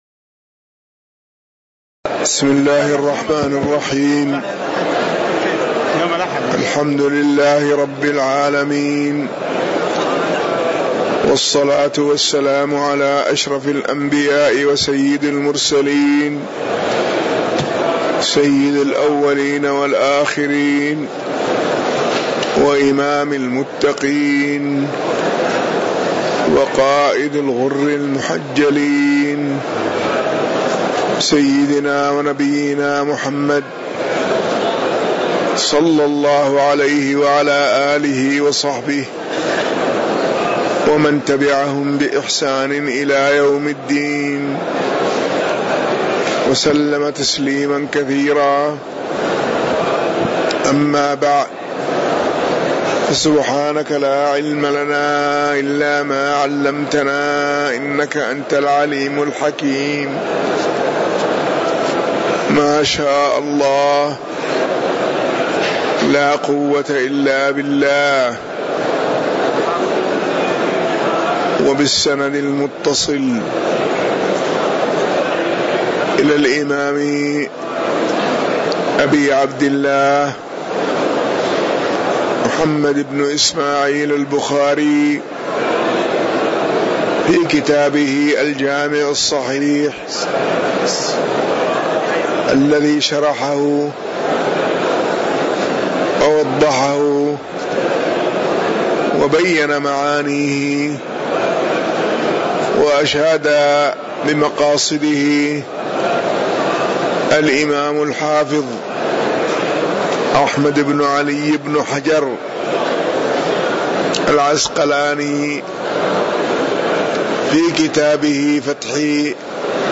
تاريخ النشر ١٣ شعبان ١٤٣٩ هـ المكان: المسجد النبوي الشيخ